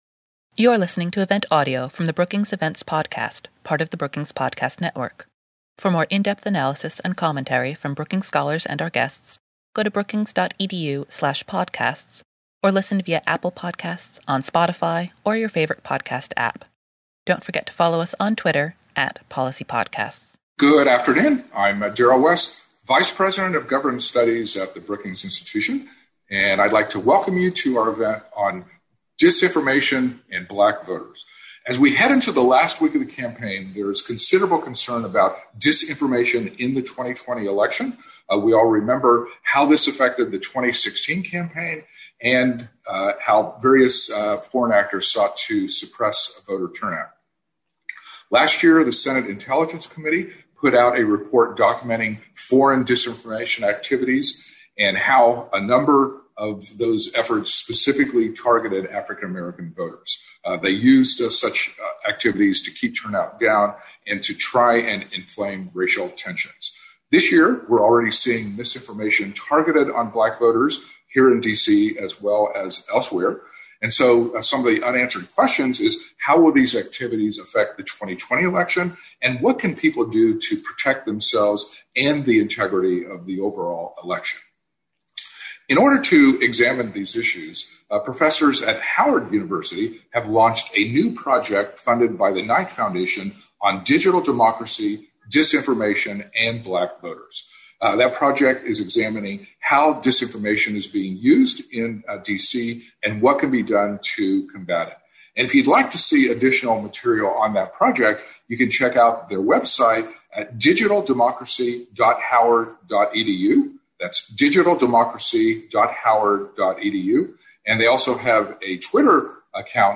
On October 28, Governance Studies at Brookings and Howard University will cohost a webinar to focusing on Black voters, disinformation, and digital democracy in 2020.